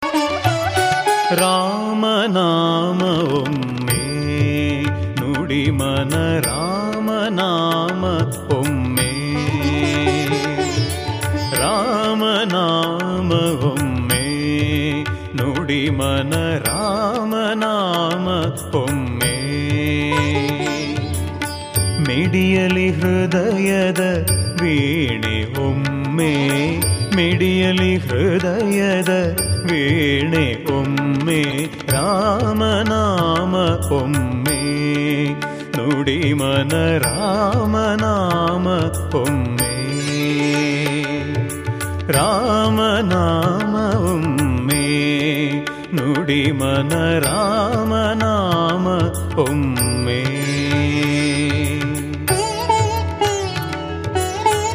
Kannada Bhajans